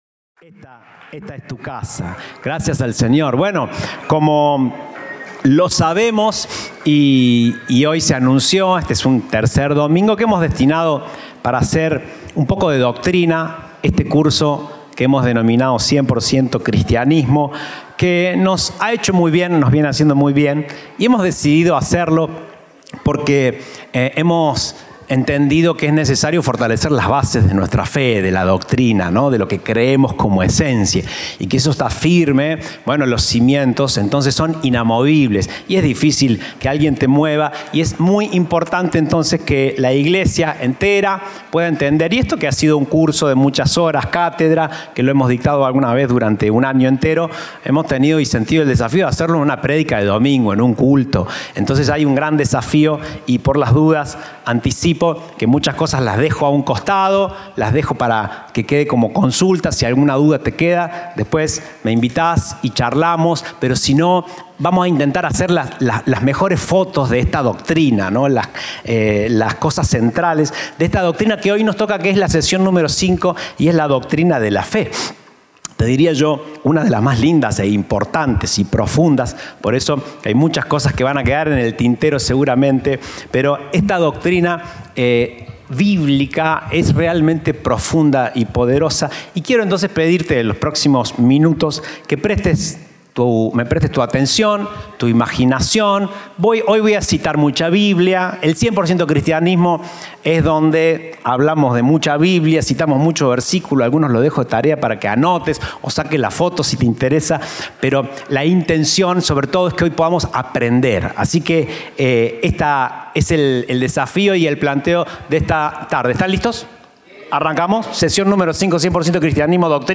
Presentamos esta Serie de mensajes titulada “100% Cristianismo”.